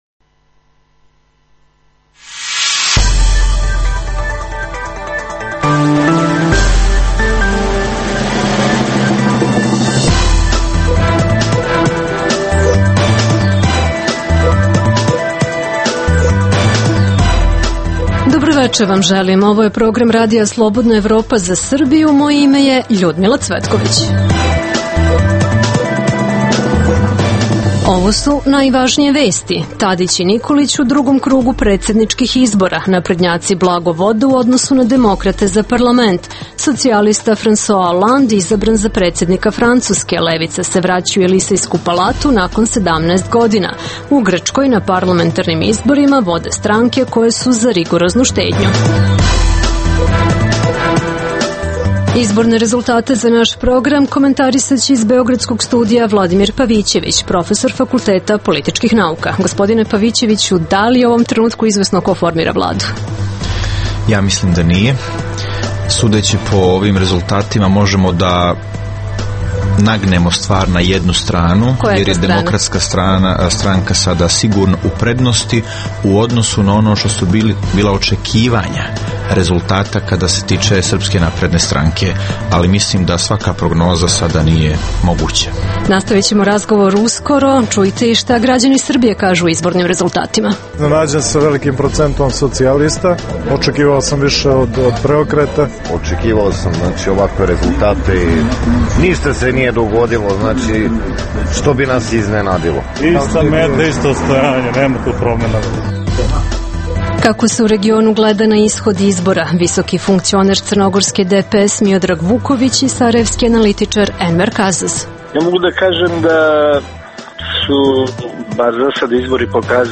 O tome u beogradskom studiju RSE razgovaramo